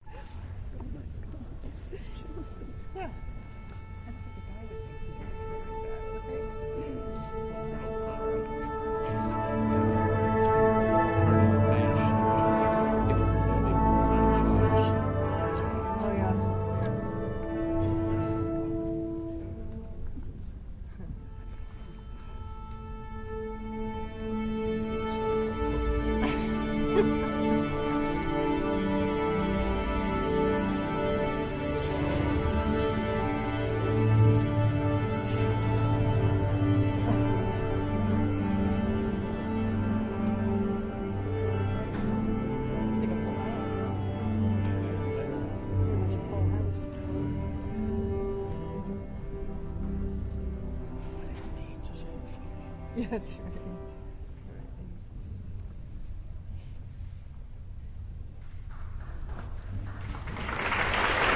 前交响乐团1
描述：交响乐开始前的管弦乐队和观众的声音
Tag: 观众 调教 乐团